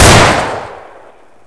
sol_reklam_link sag_reklam_link Warrock Oyun Dosyalar� Ana Sayfa > Sound > Weapons > Glock Dosya Ad� Boyutu Son D�zenleme ..
WR_fire.wav